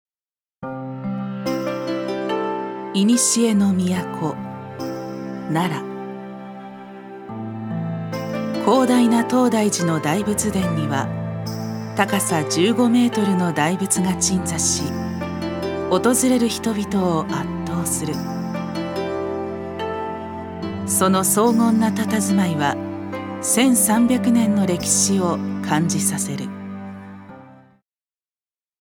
ナレーション５